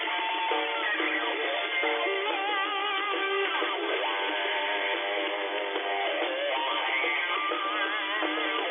It is synthesized music, but it sure picked up the beat to something resembling metal. Easier on the ear!